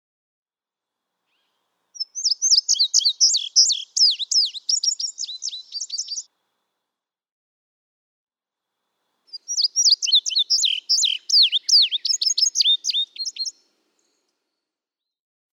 Example 1. Indigo bunting: One song from each of two neighboring indigo buntings with similar songs (♫200).
Quabbin Park, Ware, Massachusetts.
♫200—one song from each of two neighboring males
200_Indigo_Bunting.mp3